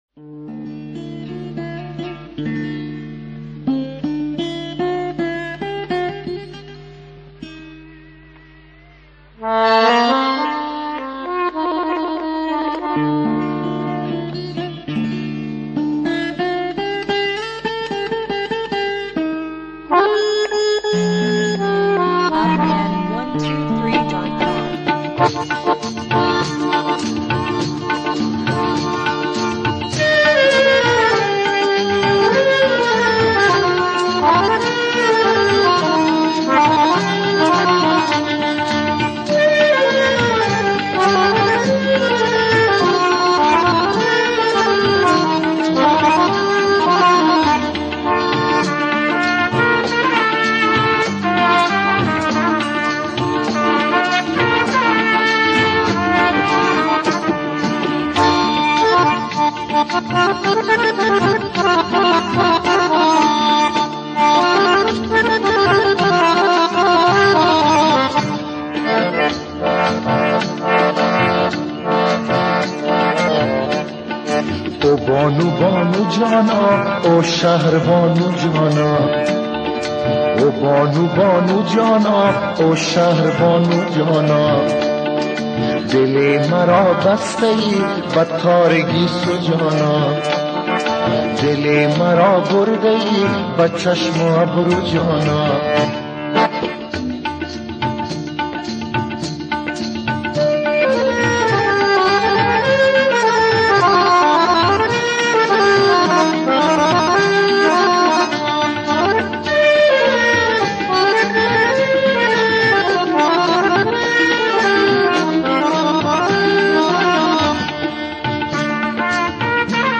آهنگ افغانی